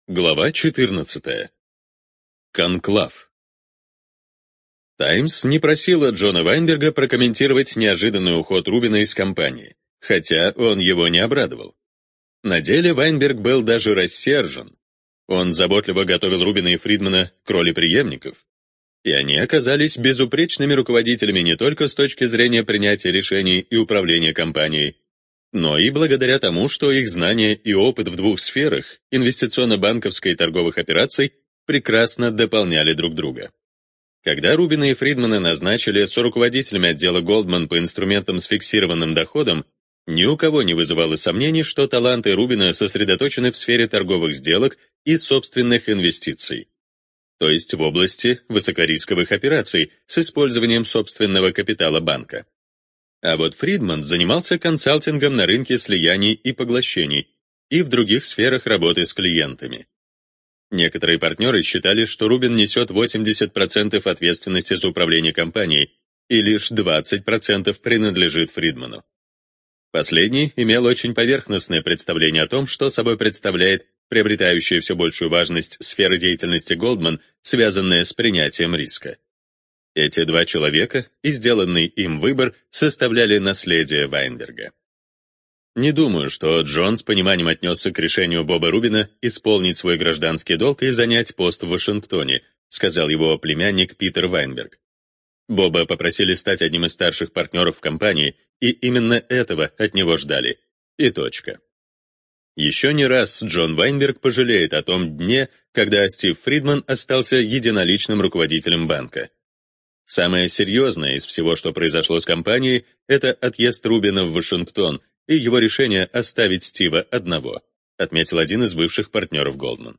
ЖанрДеловая литература